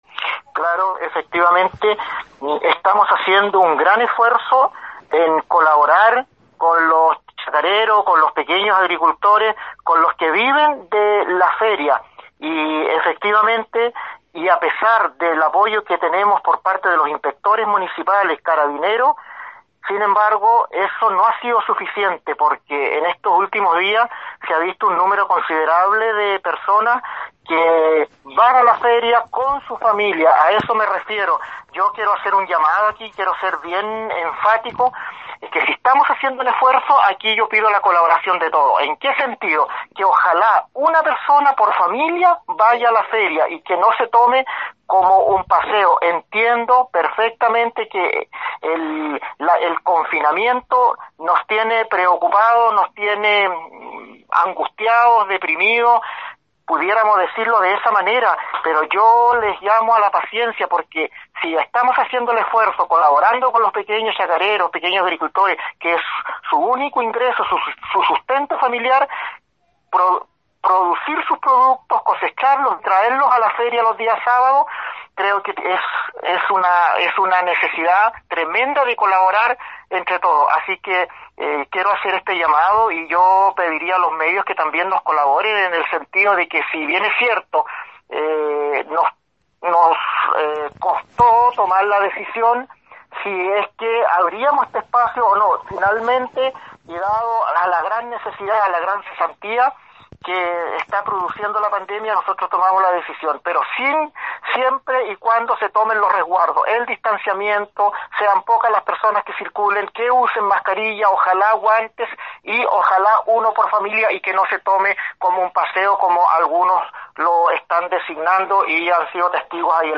Alcalde Pinuer hace un llamado al autociudado al concurrir a Ferias Libres
cuña-alcalde-por-feria-libre.mp3